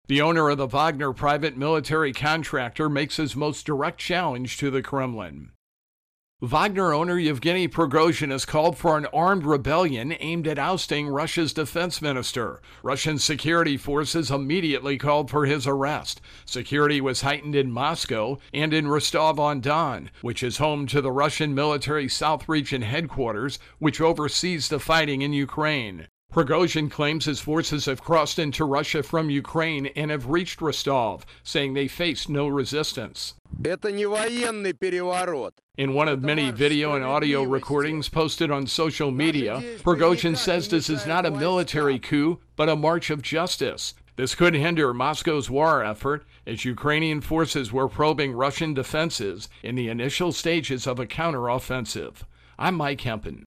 reports on Russia Ukraine War.